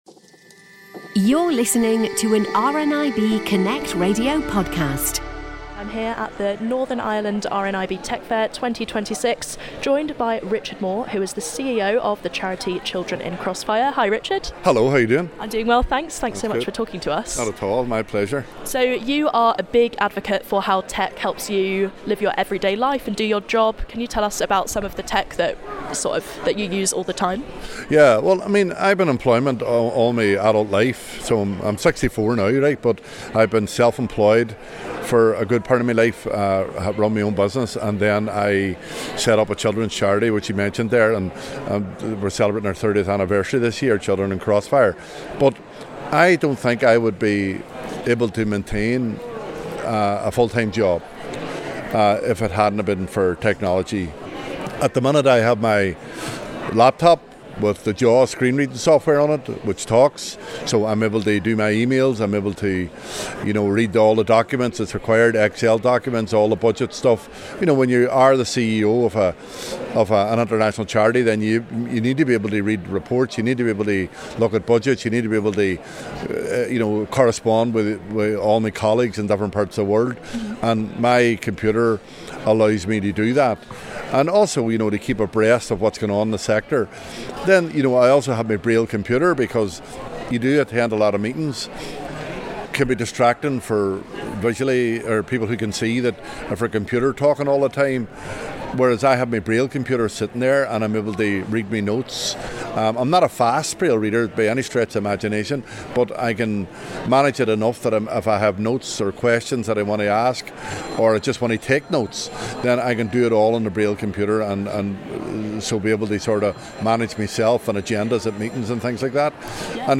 RNIB Northern Ireland Technology Fair 2026 took place on Wednesday 11th of February at the Foyle Arena in Derry/Londonderry.